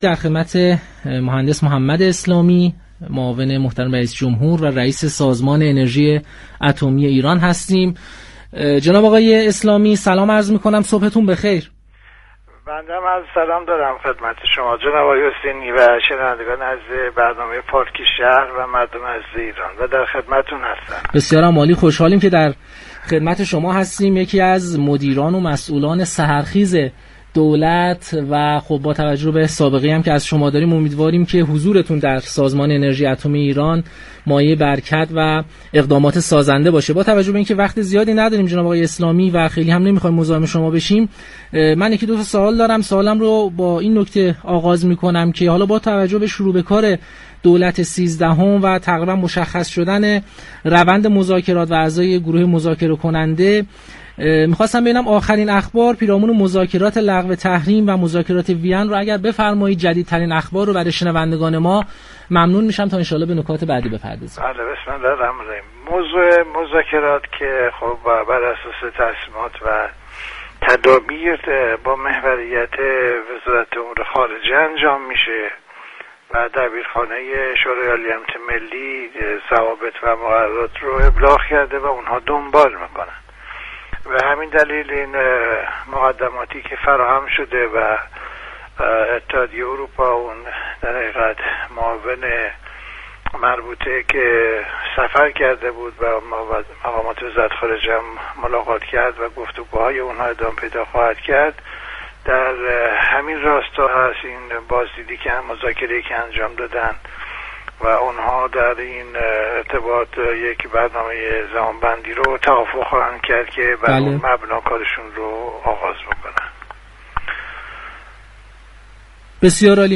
به گزارش پایگاه اطلاع رسانی رادیو تهران، محمد اسلامی معاون رئیس‌جمهوری و رئیس سازمان انرژی اتمی در گفتگو با پارك شهر رادیو تهران گفت: مذاكرات هسته‌ای بر اساس تصمیمات و تدابیر با محوریت وزارت امور خارجه و در قالب ضوابط و مقررات آژانس بین‌المللی انرژی هسته‌ای و بر مبنای جدول زمانی انجام می‌شود.